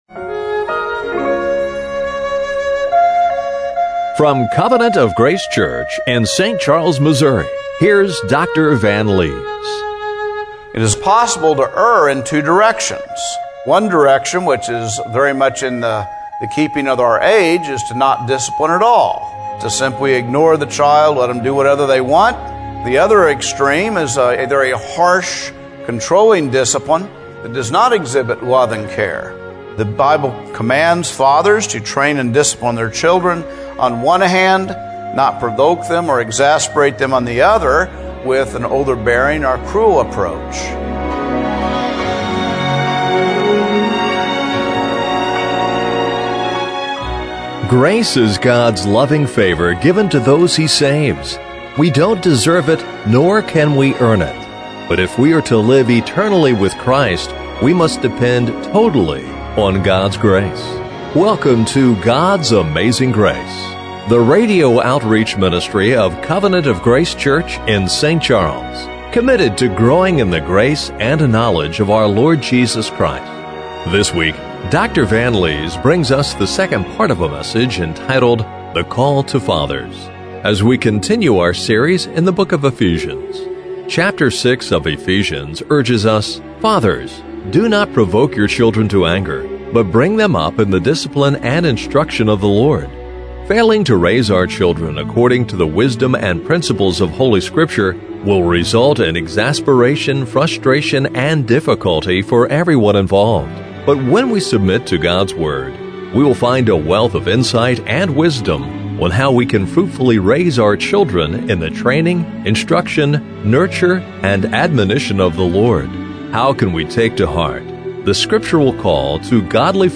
Ephesians 6:1-9 Service Type: Radio Broadcast How can we take to heart the scripture call to godly fatherhood and biblical parenting?